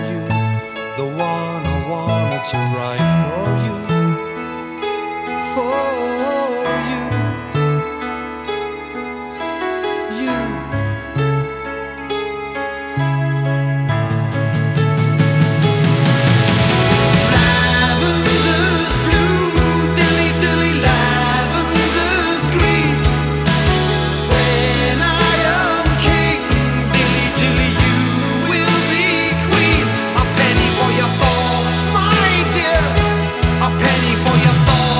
Жанр: Поп / Рок / Пост-хардкор / Хард-рок